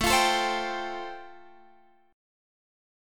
Am7#5 chord